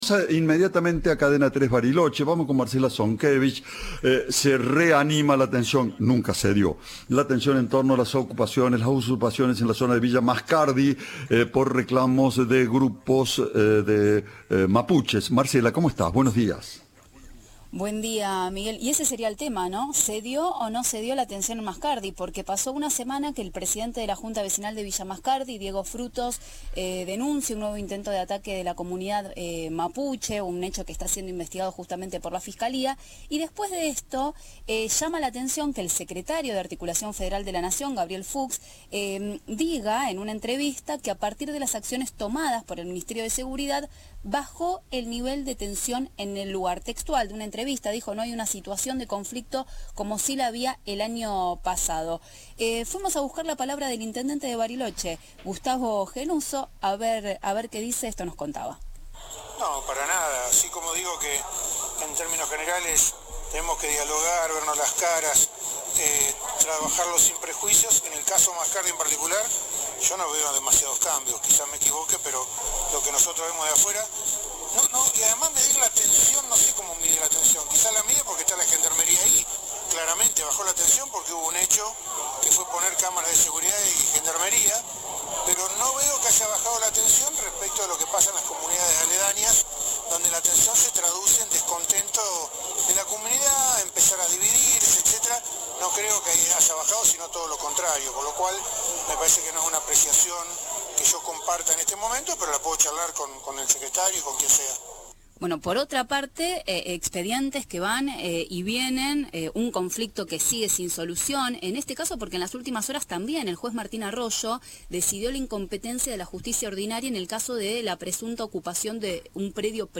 En una entrevista, el secretario de Articulación Federal de la Seguridad del gobierno nacional, Gabriel Fuks, reivindicó la intervención de la cartera de Seguridad de Nación en Villa Mascardi y aseguró que bajó la tensión en el lugar.
Informe